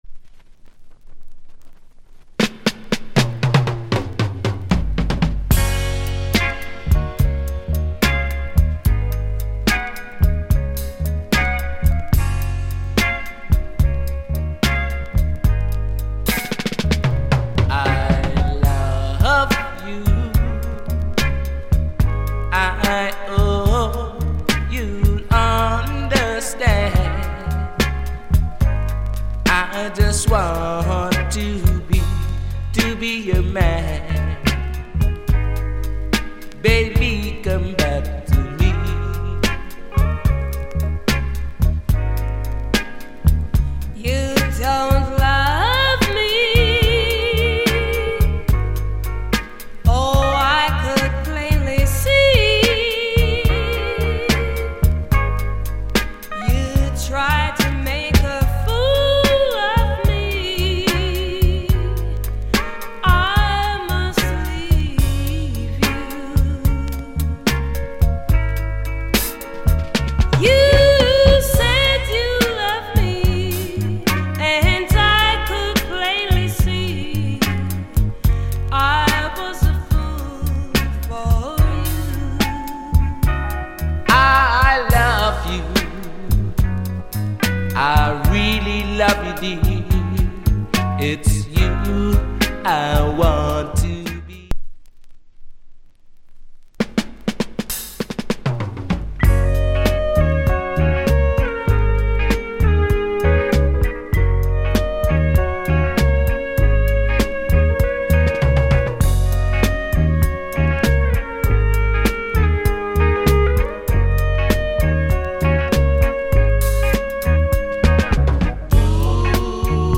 Genre Soul/R&B Ballad / Male Vocal Female Vocal